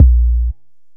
MB Kick (32).wav